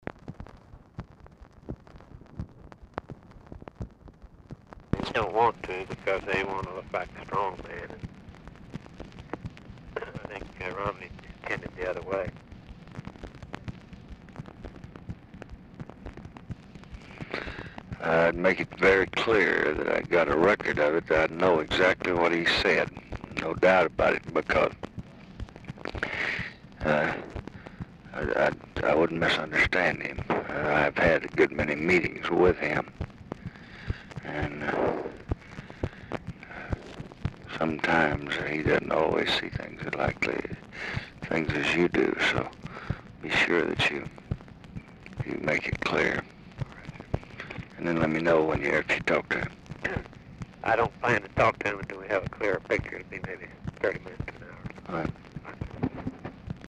Telephone conversation # 12004, sound recording, LBJ and RAMSEY CLARK, 7/24/1967, time unknown
Format Dictation belt
Location Of Speaker 1 Mansion, White House, Washington, DC